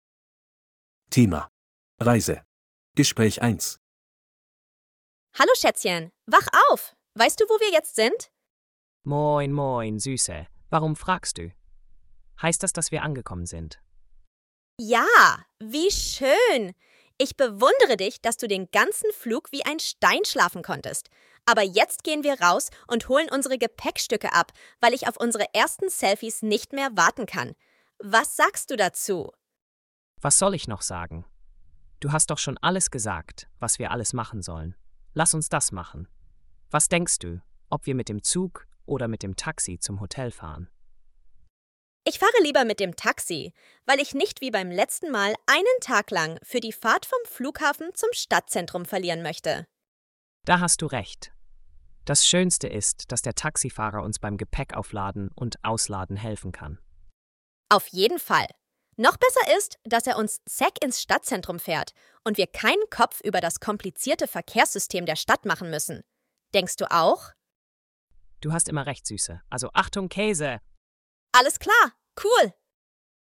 Audio text conversation 1: